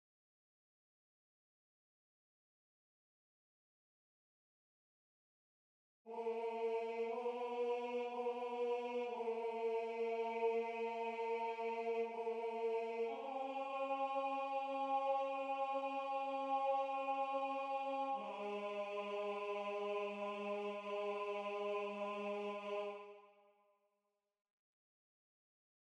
Key written in: C# Major
Type: Barbershop